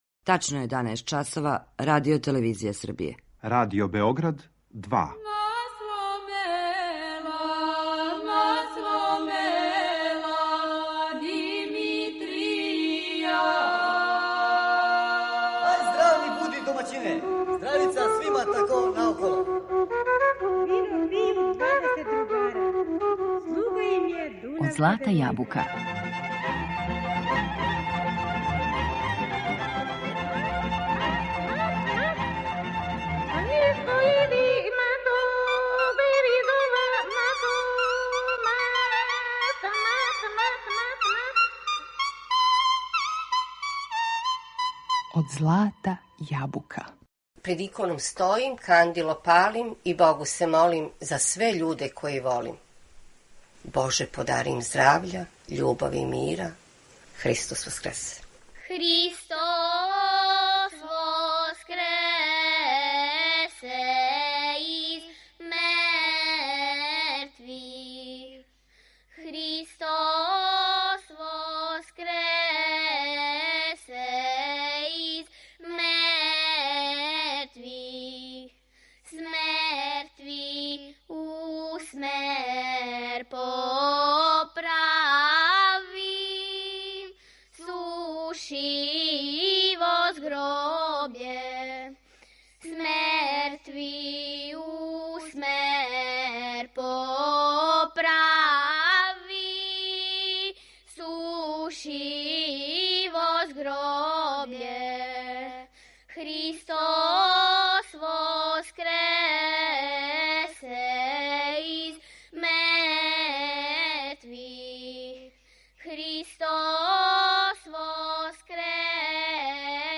Слушаћете песме које се изводе у оквиру пролећног обичајног циклуса.